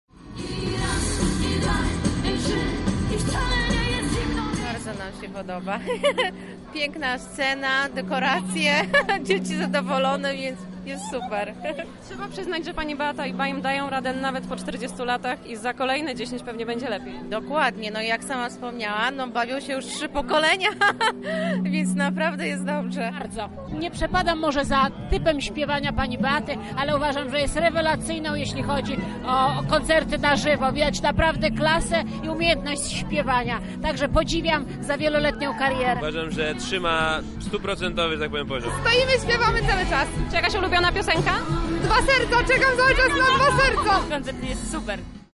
Jubileuszowy koncert na Placu Zamkowym przyciągnął tłumy. Zapytaliśmy mieszkańców Lublina o wrażenia: